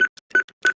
Звуки клавиатуры доступны для прослушивания там же, включая звук ввода четырехзначного пин кода